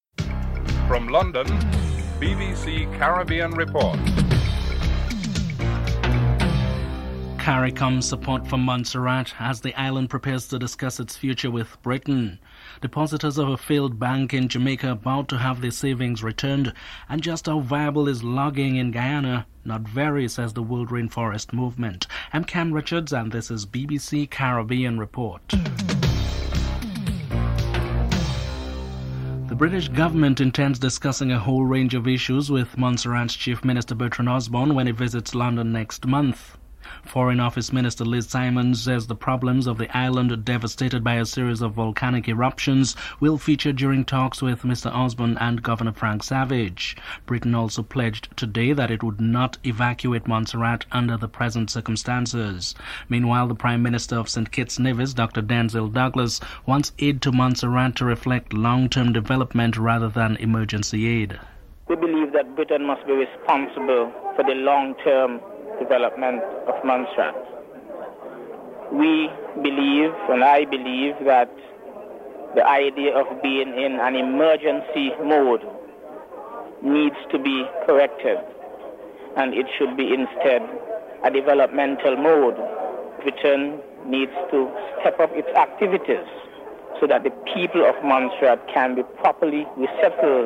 2. CARICOM’s support for Montserrat as the island prepares to discuss its future with Britain. Prime Minister of St Kitts-Nevis, Denzil Douglas is interviewed (00:29-02:11)
Vice Minister for Foreign Trade, Teodoro Maldonado is interviewed (08:02-08:39)